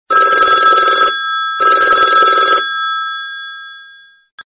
Kategori Mobiltelefon